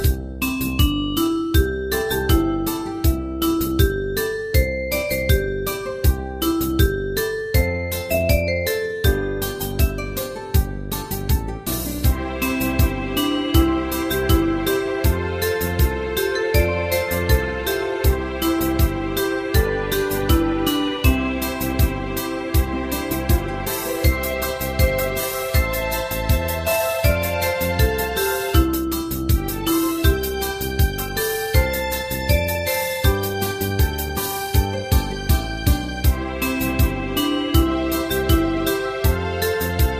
大正琴の「楽譜、練習用の音」データのセットをダウンロードで『すぐに』お届け！
カテゴリー: ユニゾン（一斉奏） .
歌謡曲・演歌